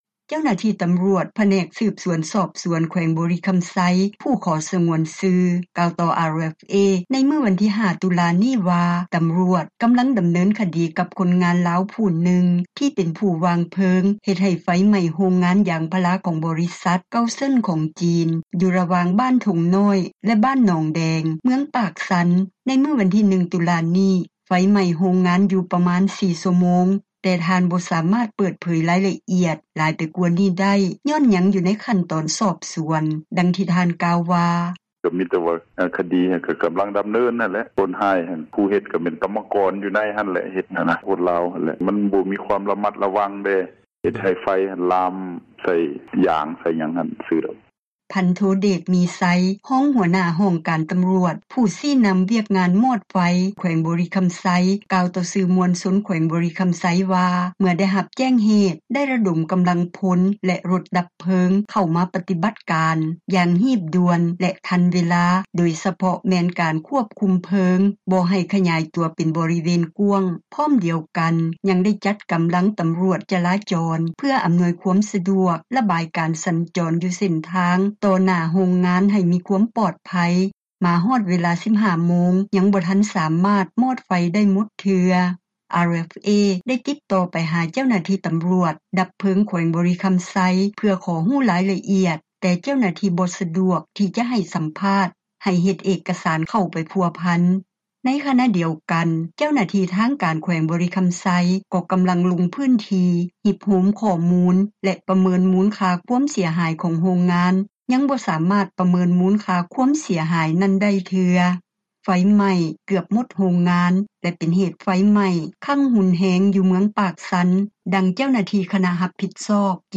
ຜູ້ຕ້ອງສົງສັຍຈູດໂຮງງານ ຖືກສອບສວນ – ຂ່າວລາວ ວິທຍຸເອເຊັຽເສຣີ ພາສາລາວ